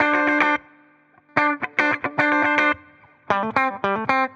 Index of /musicradar/dusty-funk-samples/Guitar/110bpm